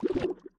Minecraft Version Minecraft Version 1.21.5 Latest Release | Latest Snapshot 1.21.5 / assets / minecraft / sounds / block / sculk / charge1.ogg Compare With Compare With Latest Release | Latest Snapshot
charge1.ogg